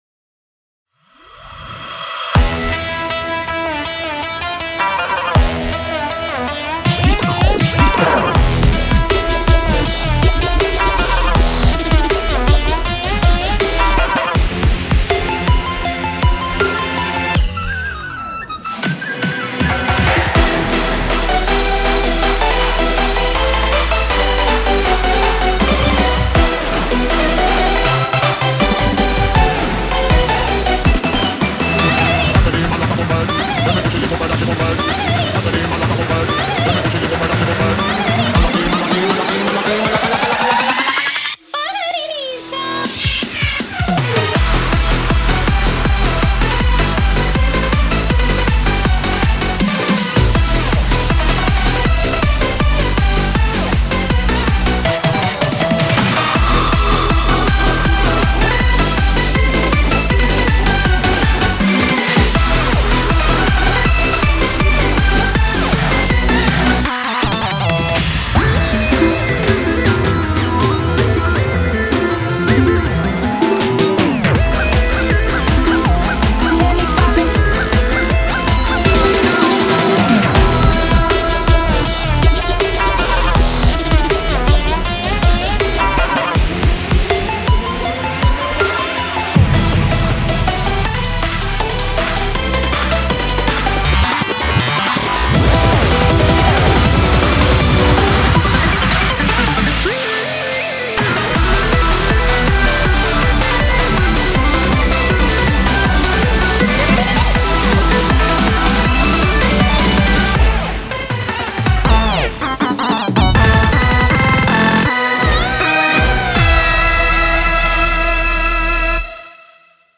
BPM160
Audio QualityPerfect (High Quality)
GENRE: ORBITALIC ORIENTAL ELECTRO